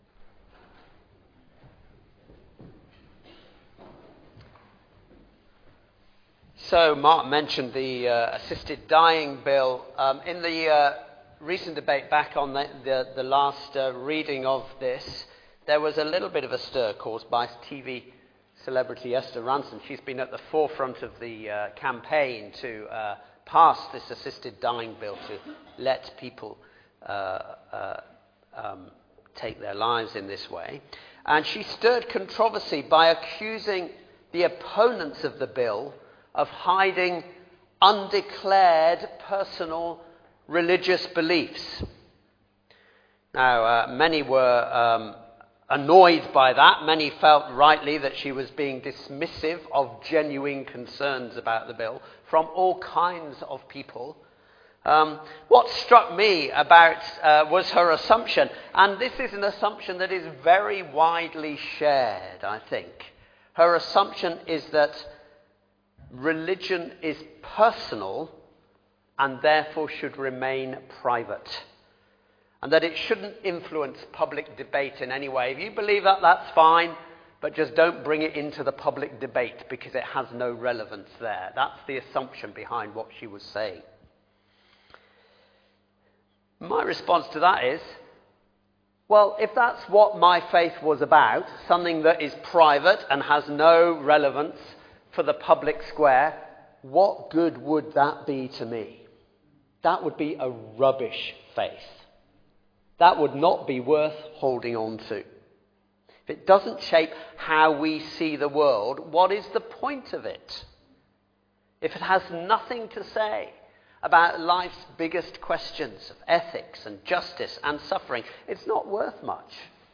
Sermon-15th-June-2025.mp3